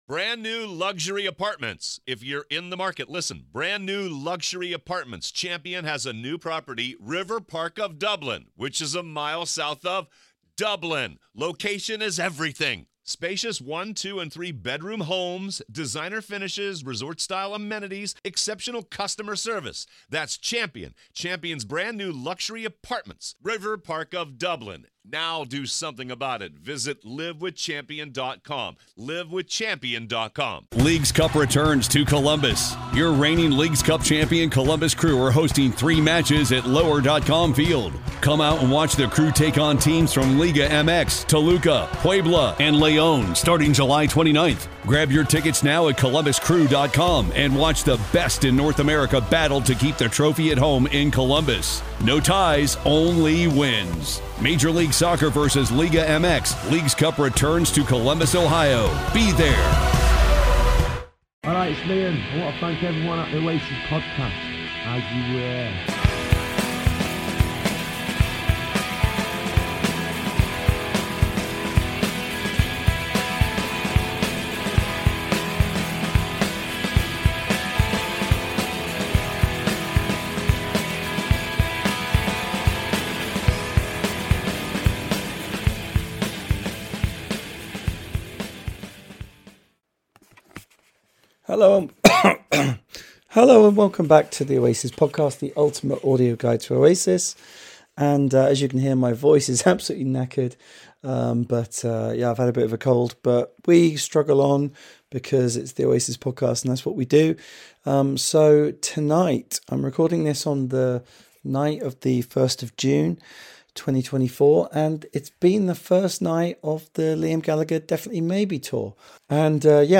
Liam is back with the DM30 tour and with one of the most exciting setlist from either brother in years. Hear me react to it (with a very dodgy voice)
Plus I have an interview